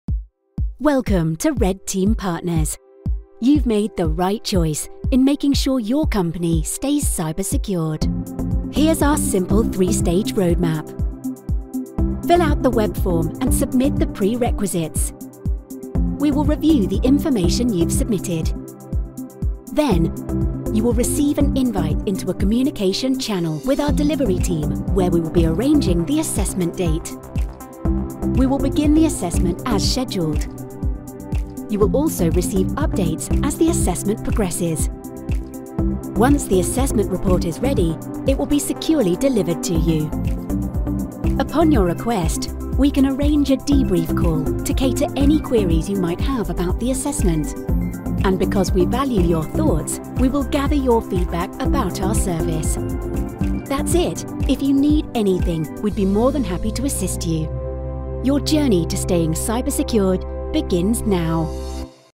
Englisch (Britisch)
Natürlich, Vielseitig, Freundlich, Warm, Corporate